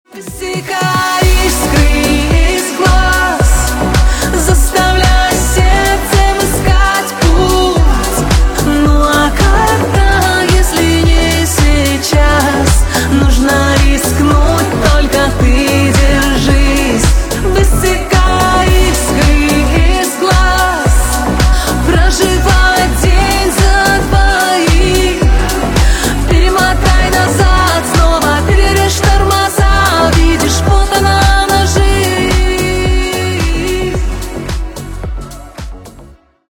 • Качество: 320, Stereo
красивые
грустные